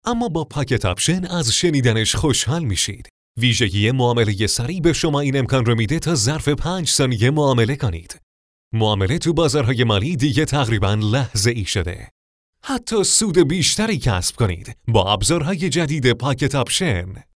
Male
Young
Adult